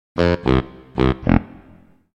Cartoon-and-game-failure-sound-effect.mp3